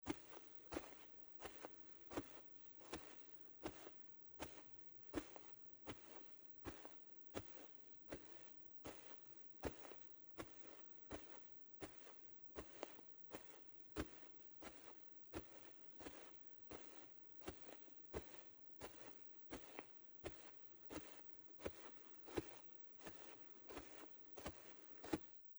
在薄薄的积雪中轻轻的行走2－YS070525.mp3
通用动作/01人物/01移动状态/02雪地/在薄薄的积雪中轻轻的行走2－YS070525.mp3